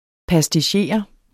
Udtale [ pasdiˈɕeˀʌ ]